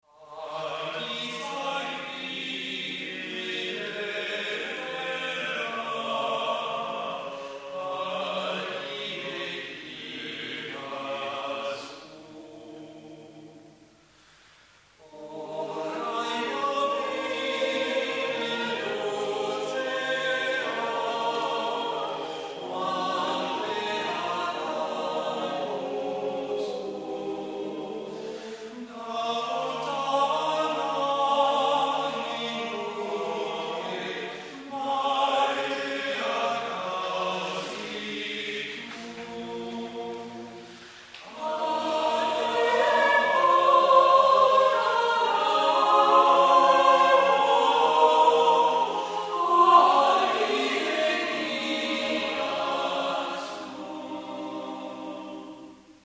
weltlich ; Volkstümlich ; Liedsatz
SAATTBB (7 gemischter Chor Stimmen )
E dorisch